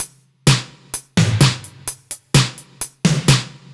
Index of /musicradar/french-house-chillout-samples/128bpm/Beats
FHC_BeatC_128-03_NoKick.wav